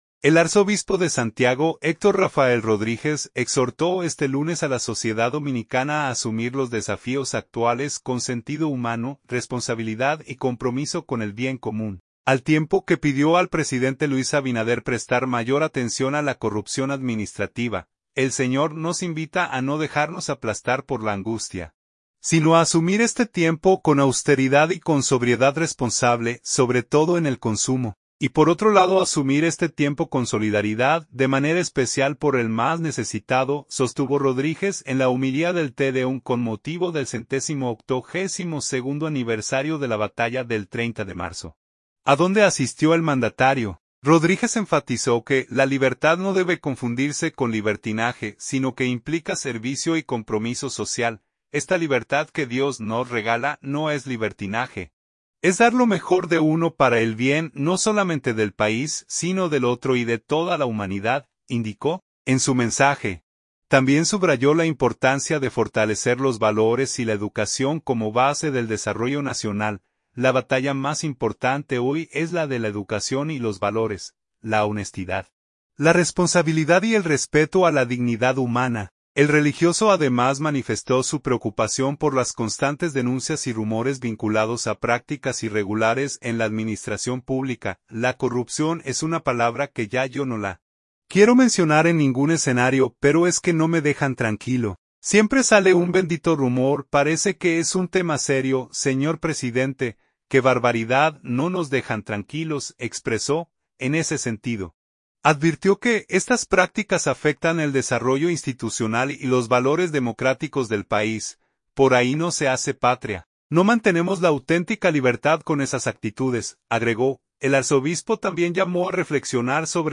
“El Señor nos invita a no dejarnos aplastar por la angustia, sino a asumir este tiempo con austeridad y con sobriedad responsable, sobre todo en el consumo, y por otro lado asumir este tiempo con solidaridad, de manera especial por el más necesitado”, sostuvo Rodríguez en la homilía del tedeum con motivo del 182 aniversario de la Batalla del 30 de Marzo, a donde asistió el mandatario.